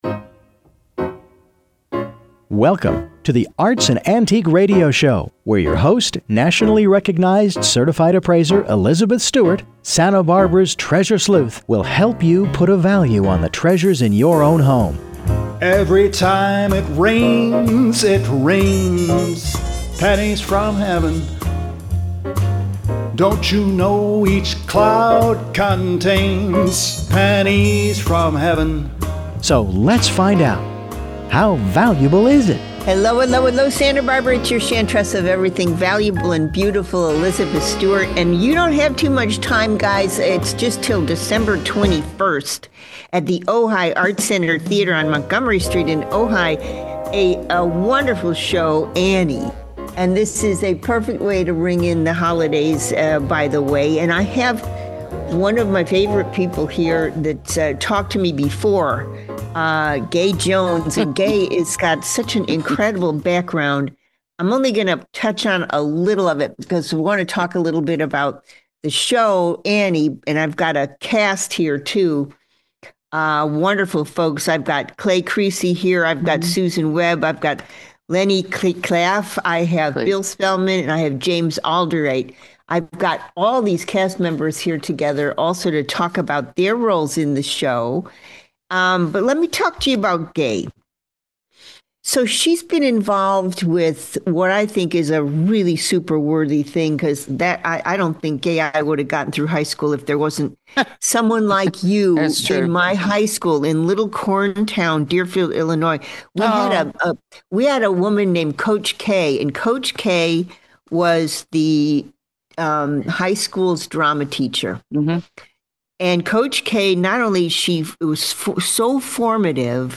joins actors from Ojai Art Center Theater‘s production of Annie, which runs through December 21.